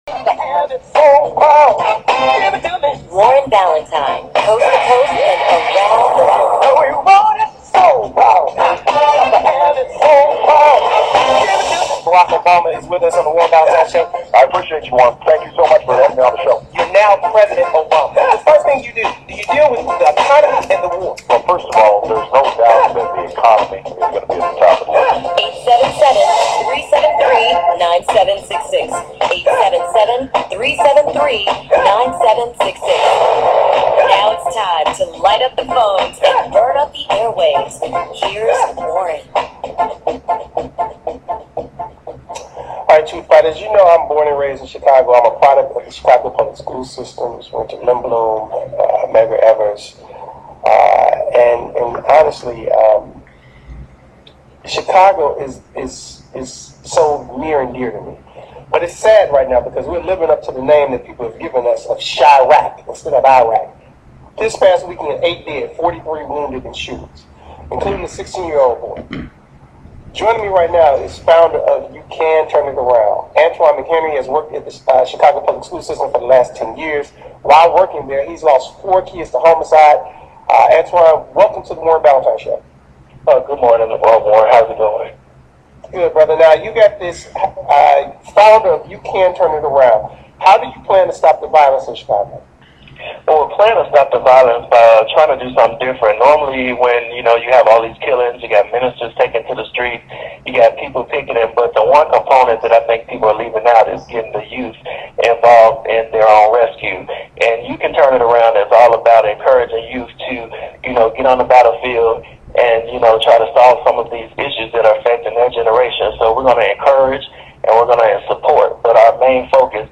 U Turn Interview with Warren Ballentine